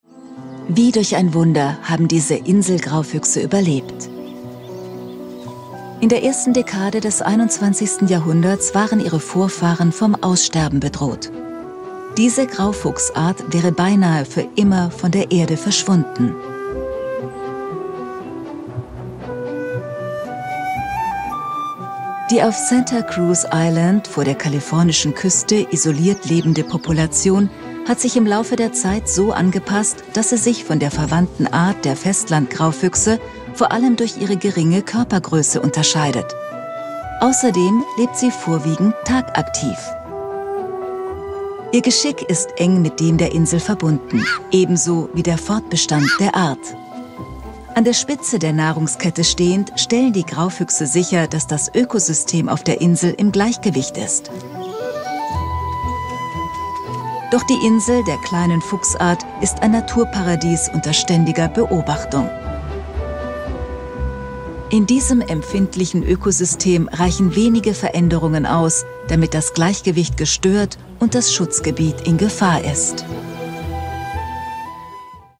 Ausgebildete Sprecherin und Schauspielerin mit variabler warmer Stimme, bekannt aus Radio- und TV.
Sprechprobe: Sonstiges (Muttersprache):
german female voice over talent.
Doku - Wie der Graufuchs eine Insel rettete - arte.mp3